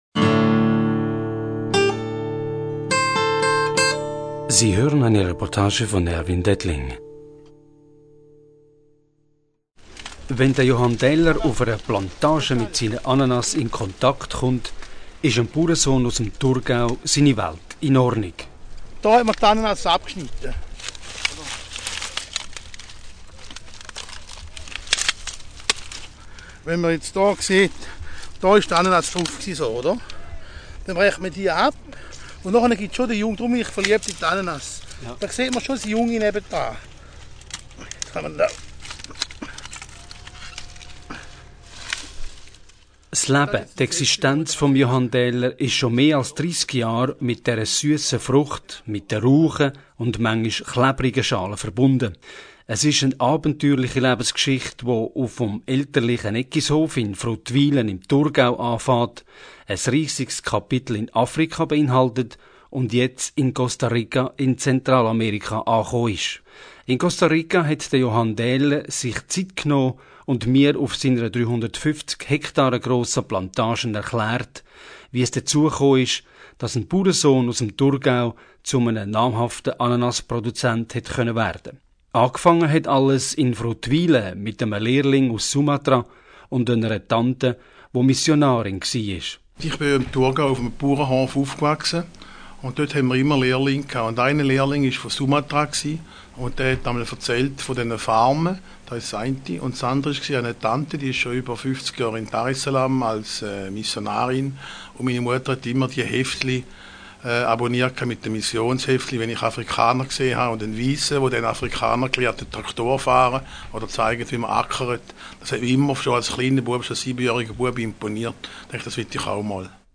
Diesmal in Costa Rica. Zur Radioreportage Weitere Reportagen